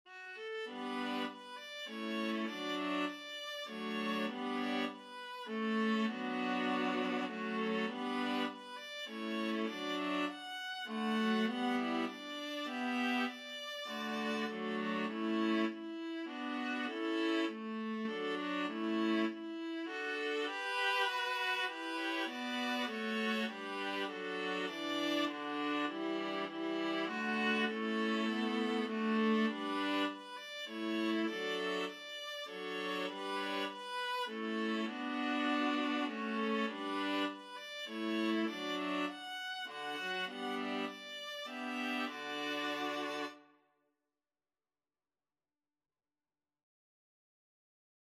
Free Sheet music for Viola Quartet
3/4 (View more 3/4 Music)
D major (Sounding Pitch) (View more D major Music for Viola Quartet )
Viola Quartet  (View more Easy Viola Quartet Music)
Classical (View more Classical Viola Quartet Music)